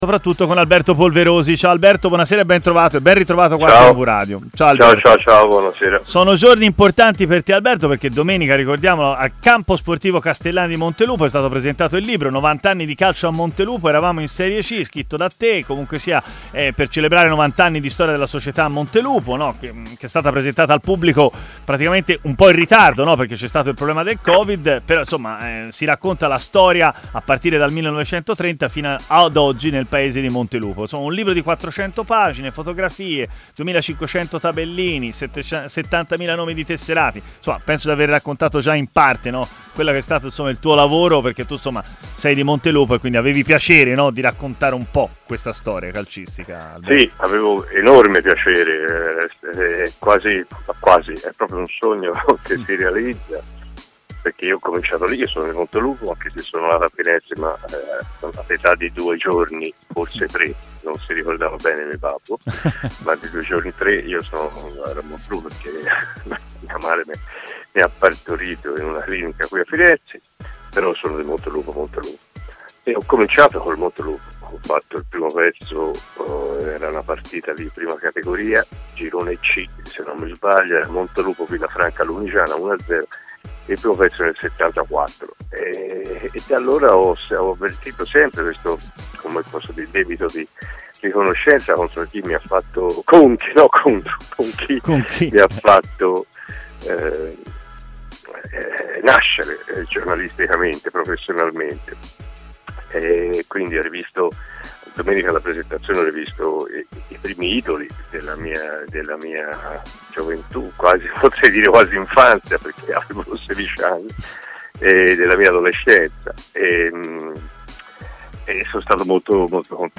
Ospite di Stadio Aperto a TMW Radio